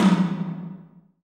TOM TOM230WL.wav